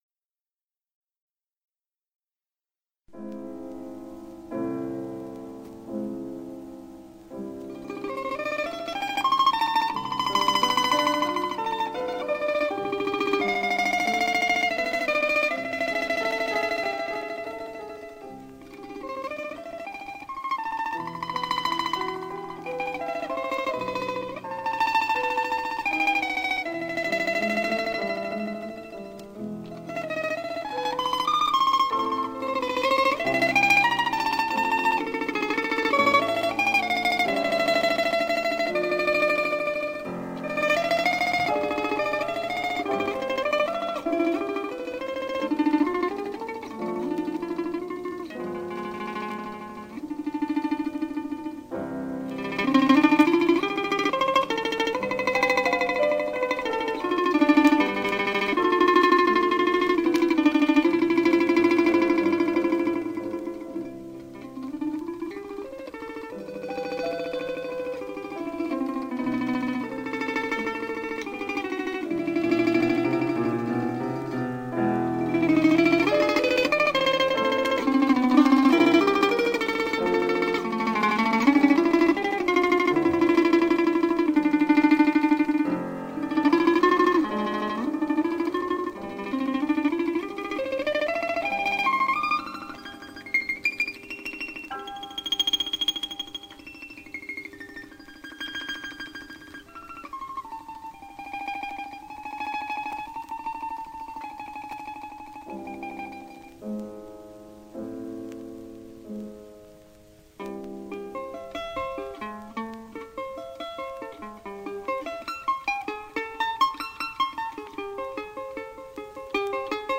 Домра в мелодиях классики